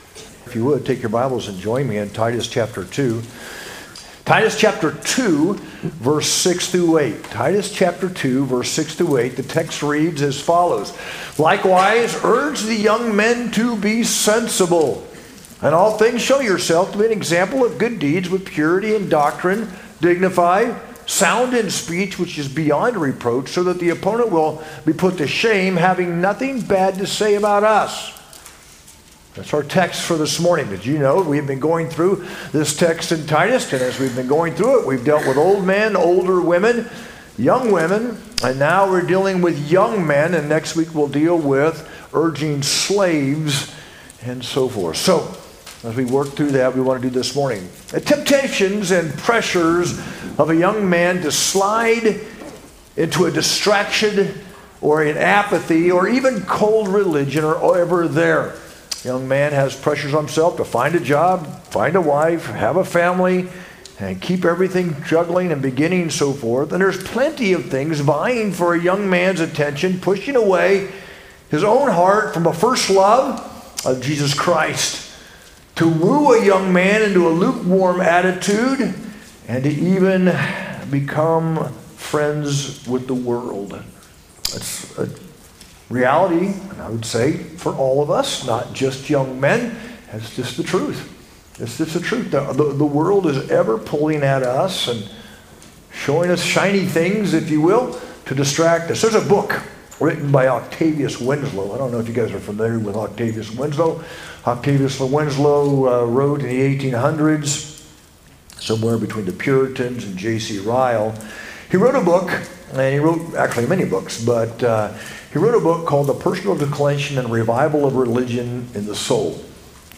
sermon-6-29-25.mp3